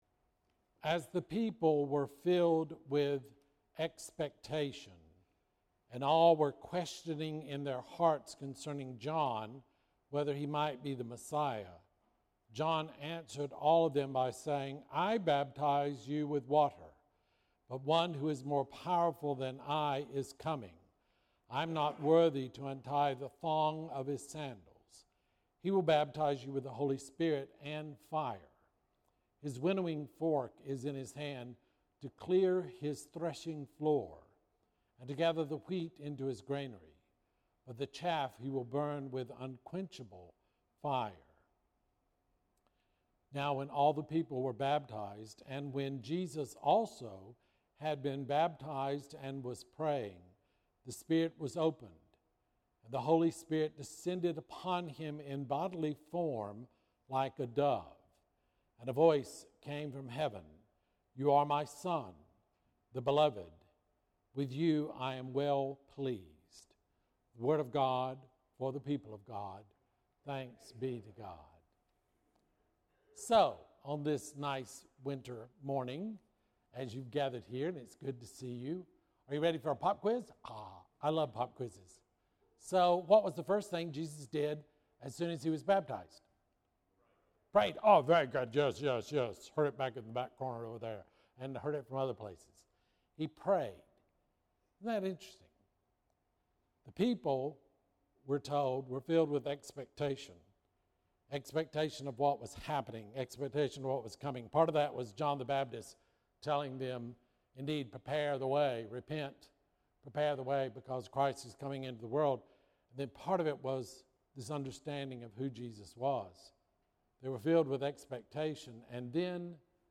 Bible Text: Luke 3:15-17, 21-22 | Preacher